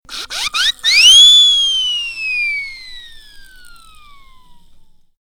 clock12.ogg